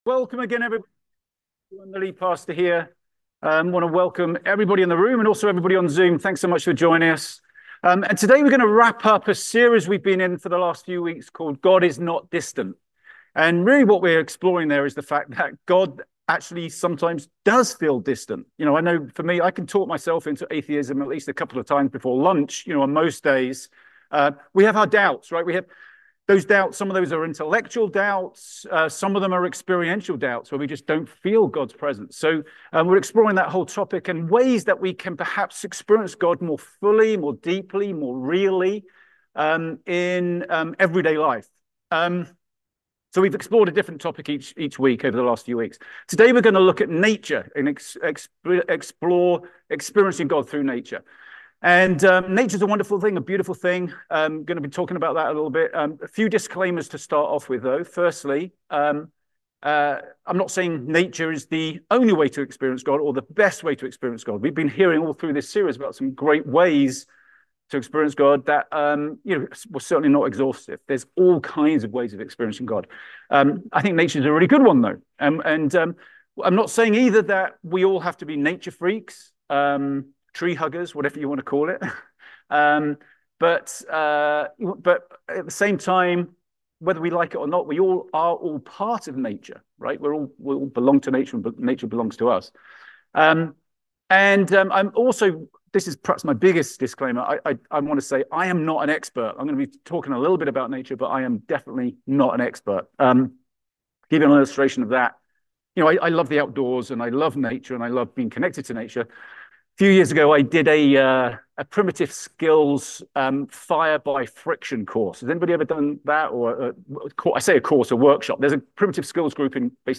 A message from the series "Breaking the Chains."